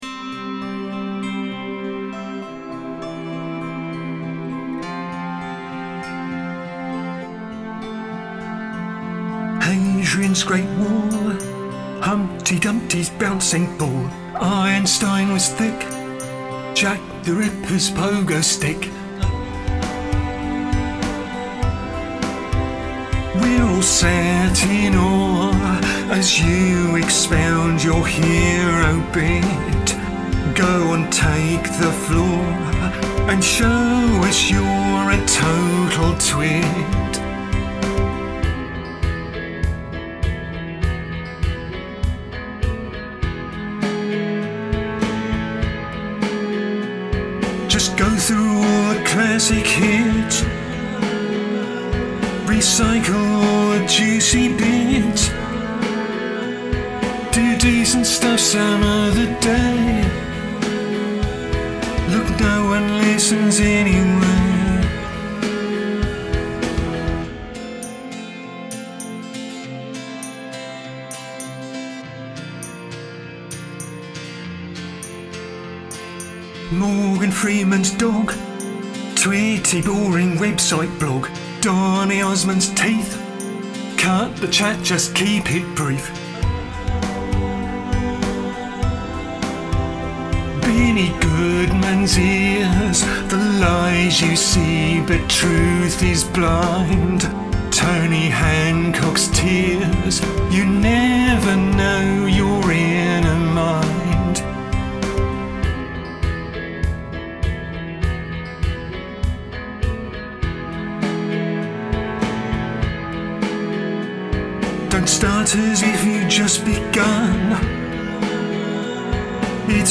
Vocal recorded: 26th May 2012, between around 11am and 2pm though this included a lot of vocal editing, especially removing annoying spikes from the main vocal.